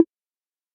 tap2.ogg